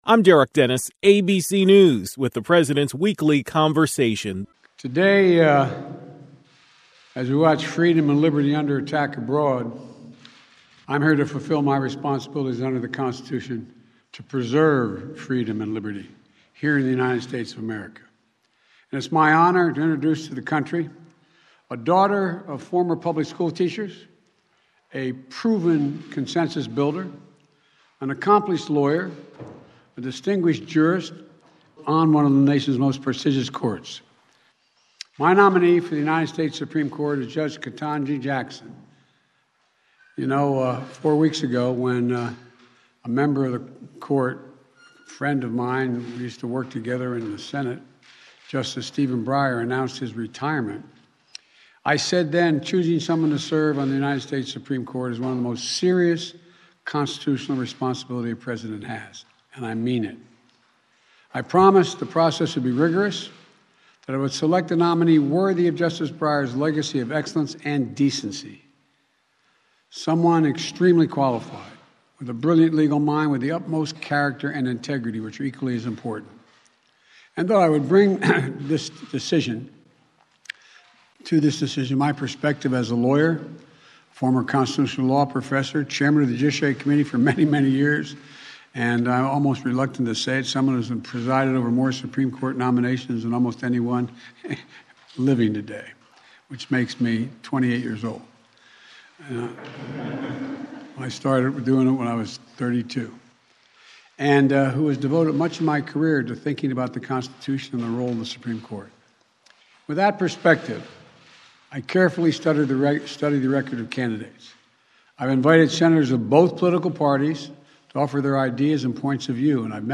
President Biden spoke about his nomination for the U.S. Supreme Court.
Here are his words: